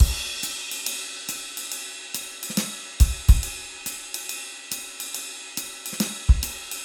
Drum Loops
Jazz 3
Swing / 140 / 4 mes
JAZZ 1- 140.mp3